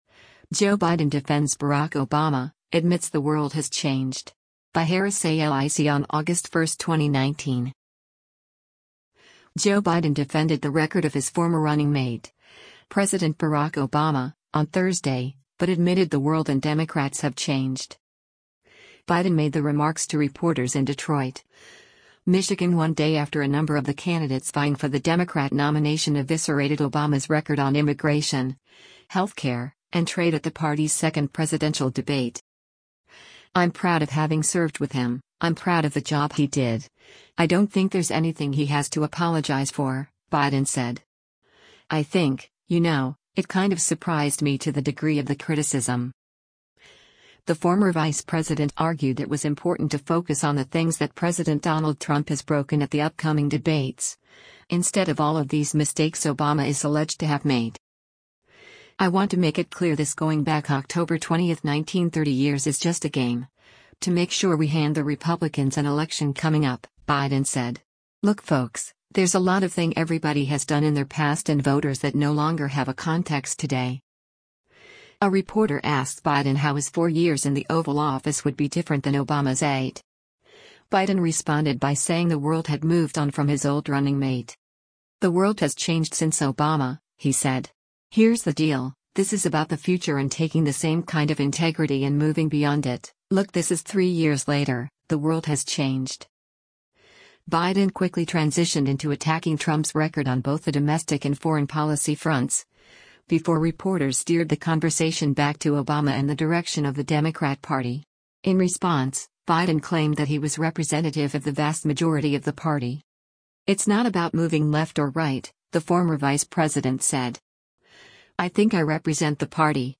Biden made the remarks to reporters in Detroit, Michigan one day after a number of the candidates vying for the Democrat nomination eviscerated Obama’s record on immigration, healthcare, and trade at the party’s second presidential debate.